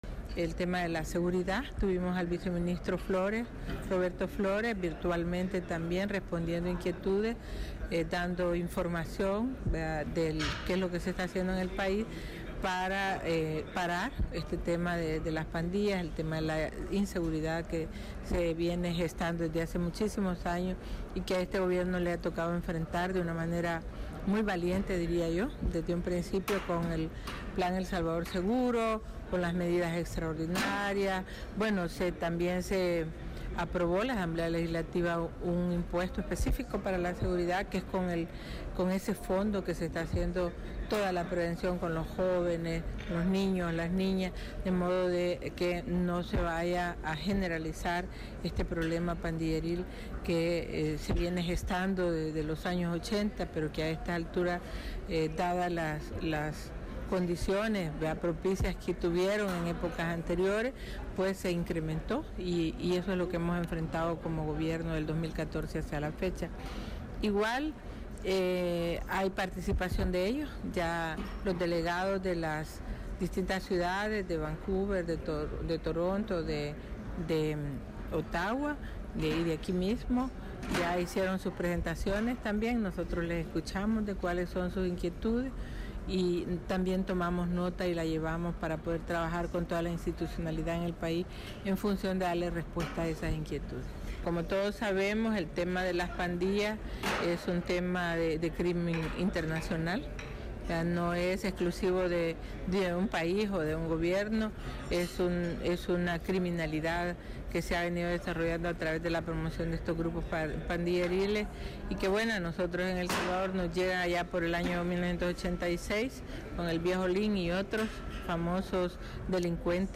Así, cuando visitan El Salvador, terminan por enamorarse de sus raíces, por estas razones es muy importante para el gobierno salvadoreño este diálogo, dice la Viceministra Magarín en entrevista con Radio Canadá Internacional.